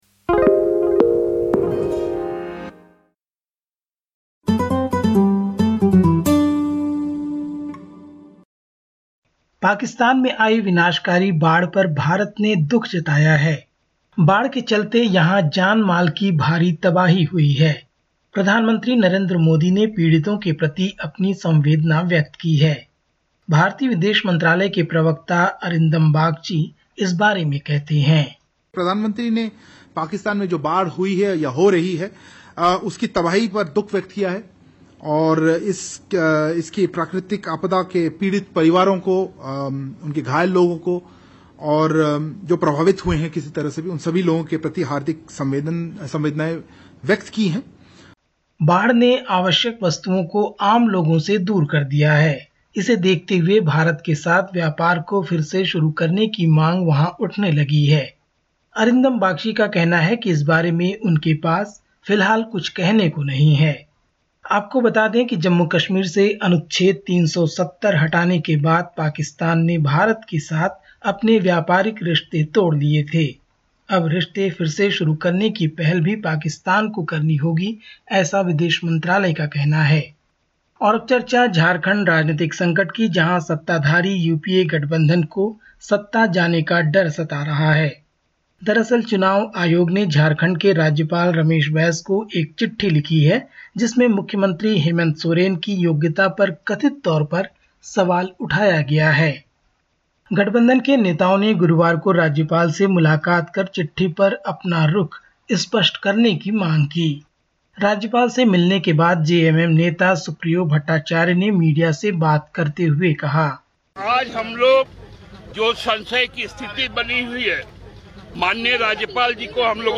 Listen to the latest SBS Hindi news from India. 02/09/2022